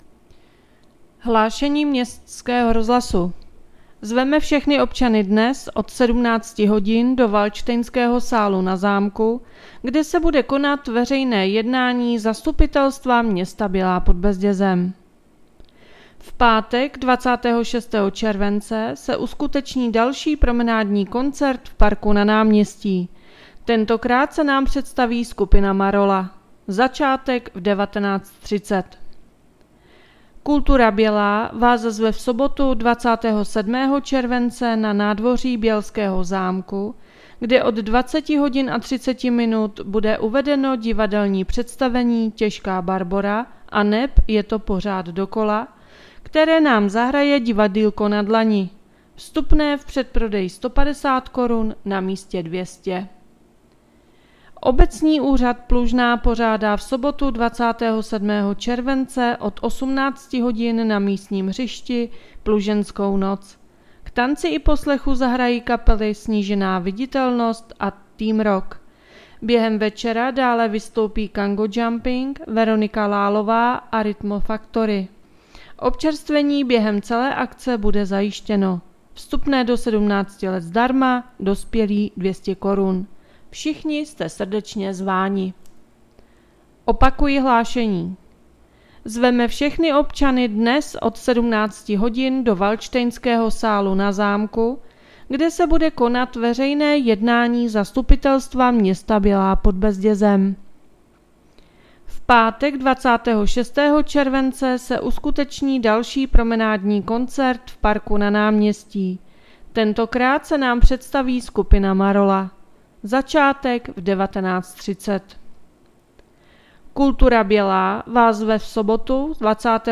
Hlášení městského rozhlasu 26.7.2024